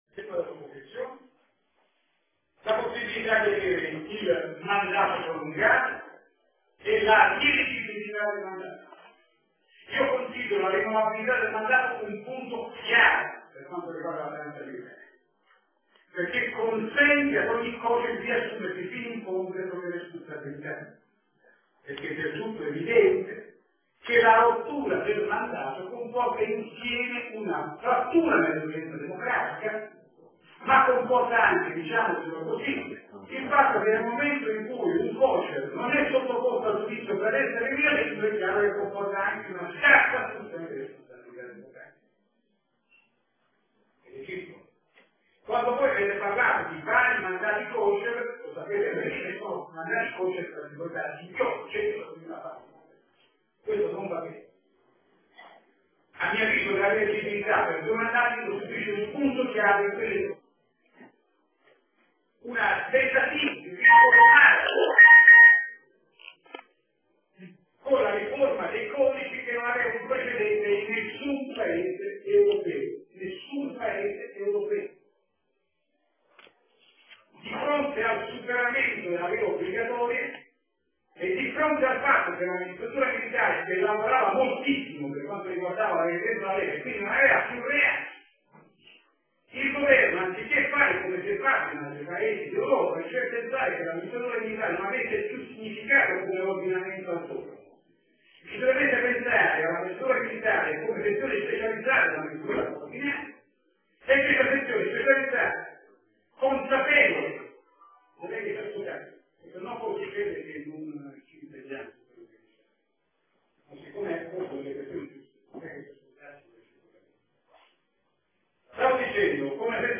L’INCONTRO DI BOLOGNA CON I DEMOCRATICI DI SINISTRA
Intervento on. Minniti